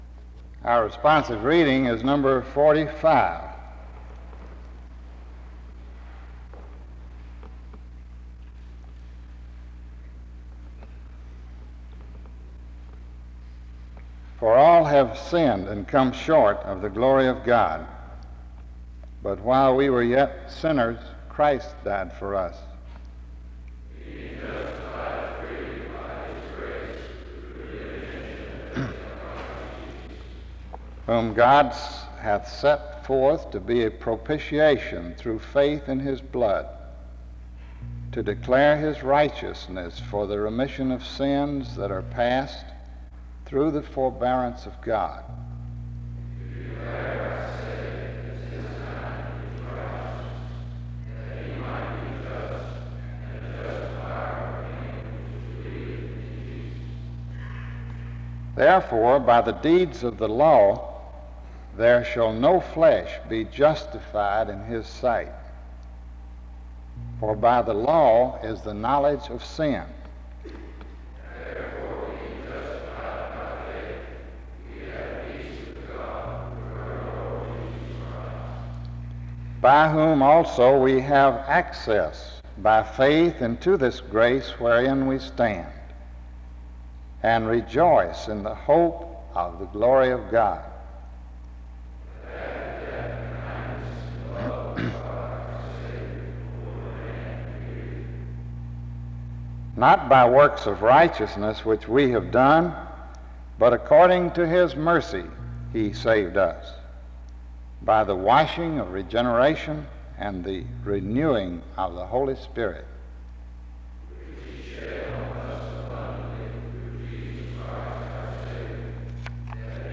The service begins with a responsive reading (0:00-2:13). After which, a prayer Is offered (2:14-4:11).
He concludes by challenging his audience to stop neglecting these four aspects of Christianity (19:32-20:57). He closes with a benediction (20:58-21:07).